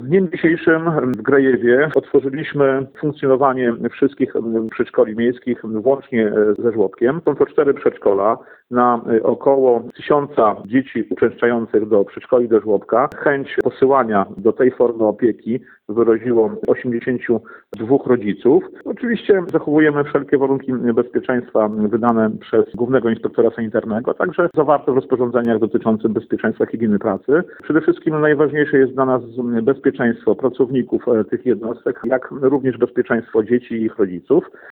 Jak mówi Dariusz Latarowski, burmistrz Grajewa, po wnikliwej analizie i konsultacjach z dyrektorami tych jednostek oraz Sanepidem zapadła decyzja, by 6 maja ponownie je uruchomić.